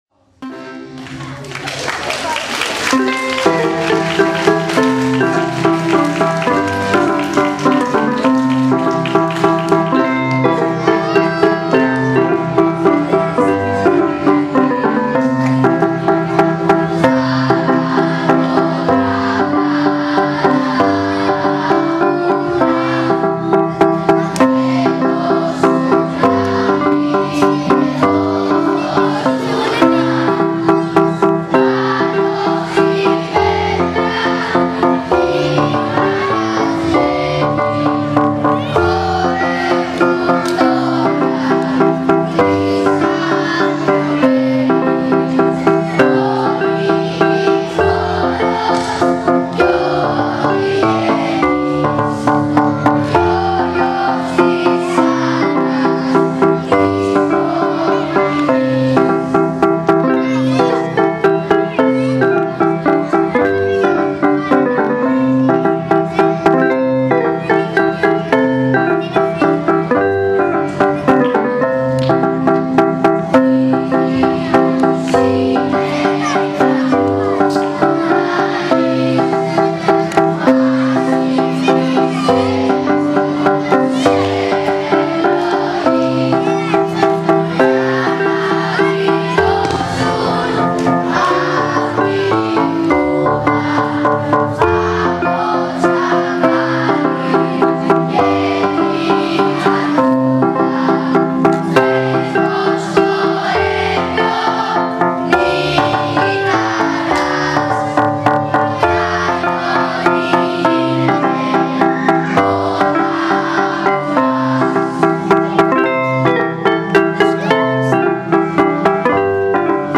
Για όλους εμάς που απολαύσαμε τους μαθητές μας στο κείμενο και τα τραγούδια της παράστασης για την επέτειο της 25ης Μαρτίου αλλά και για όλους αυτούς που δεν είχαν την ευκαιρία να τα παρακολουθήσουν, ανεβάζουμε ορισμένα από τα τραγούδια της παράστασης, όπως τα ερμήνευσε η χορωδία των μαθητών υπό την καθοδήγηση της μουσικού του σχολείου μας. [Η ανισομέρεια του επιπέδου έντασης της μουσικής και των τραγουδιών οφείλεται σε λάθος θέση της συσκευής καταγραφής του ήχου.
Τσάμικος
ntaouli.wma